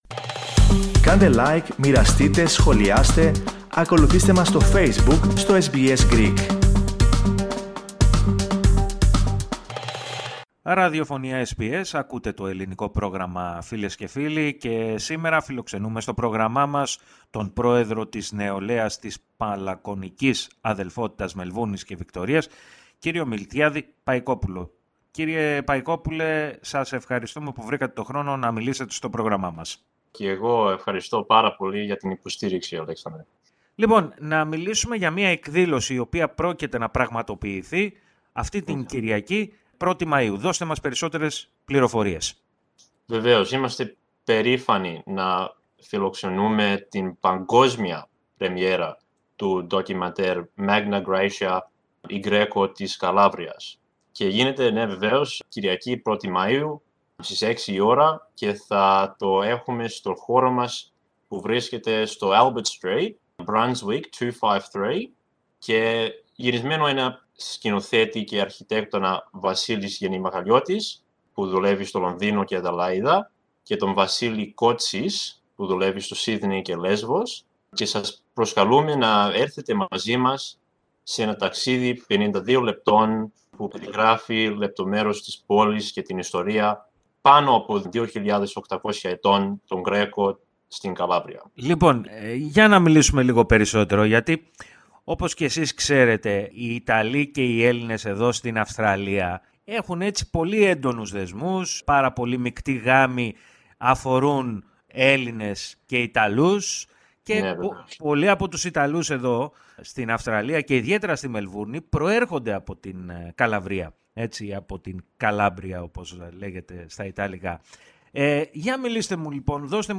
Ακούστε, όμως ολόκληρη τη συνέντευξη πατώντας το σύμβολο στο μέσο της κεντρικής φωτογραφίας.